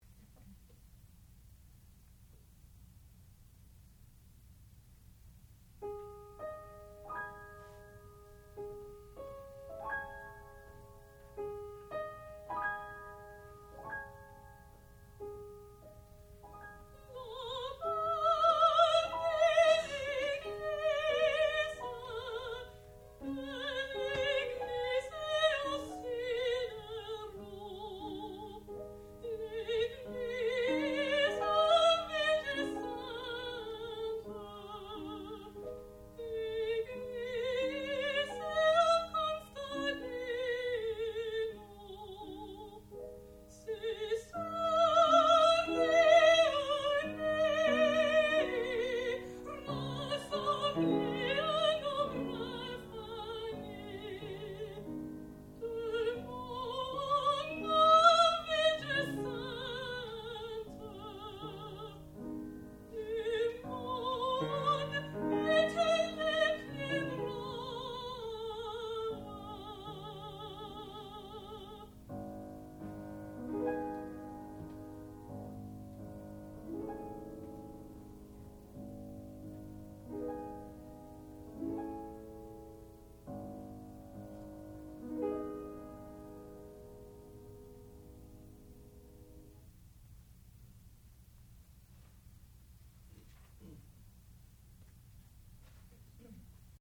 sound recording-musical
classical music
piano
Master's Recital
mezzo-soprano